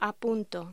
Locución: A punto
Sonidos: Hostelería